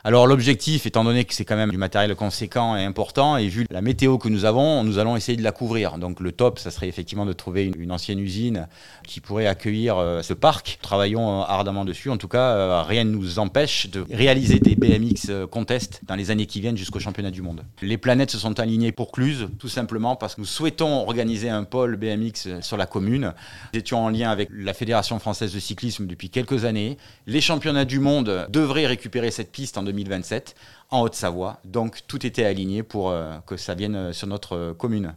Jean-Philippe Mas, le maire de Cluses revient sur l'arrivée de cette piste :